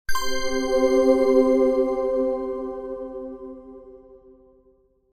notify.mp3